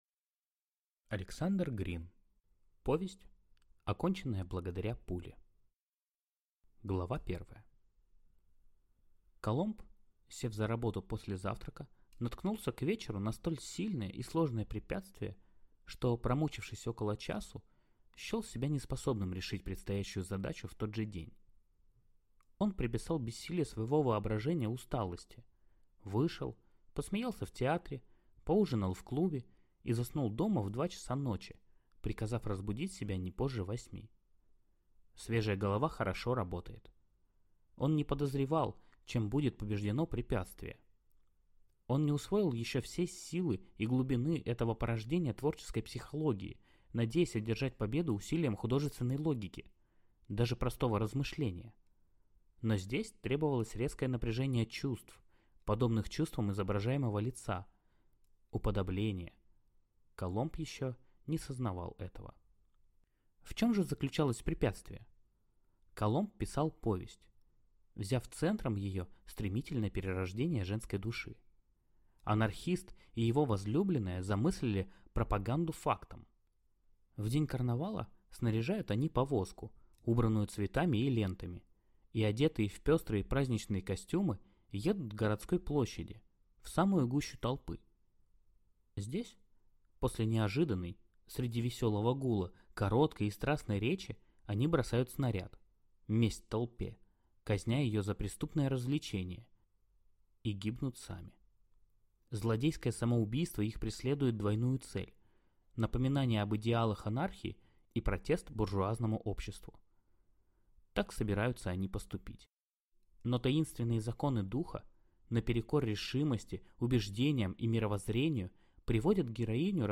Аудиокнига Повесть, оконченная благодаря пуле | Библиотека аудиокниг